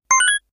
item_pickup.wav